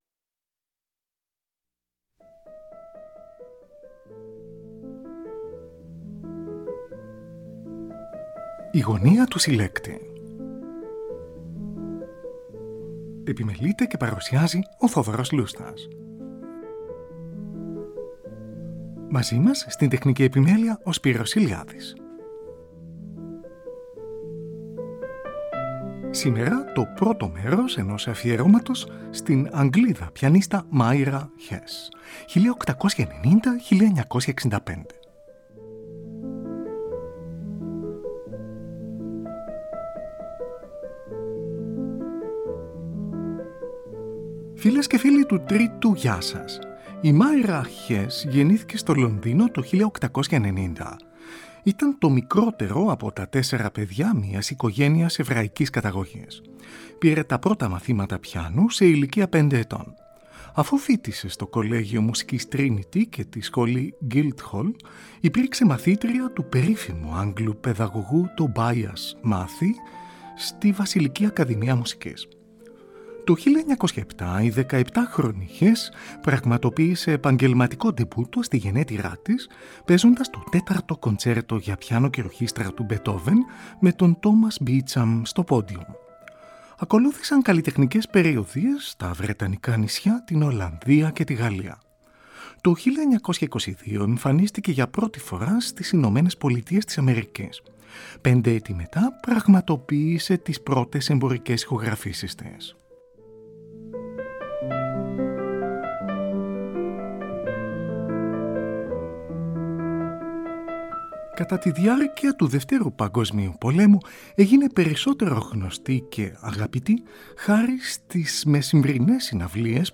ΑΦΙΕΡΩΜΑ ΣΤΗΝ ΑΓΓΛΙΔΑ ΠΙΑΝΙΣΤΑ MYRA HESS (1890-1965) (ΠΡΩΤΟ ΜΕΡΟΣ)
Ακούγονται συνθέσεις των Domenico Scarlatti, Johann Sebastian Bach, Johannes Brahms, Claude Debussy, Maurice Ravel κ.ά.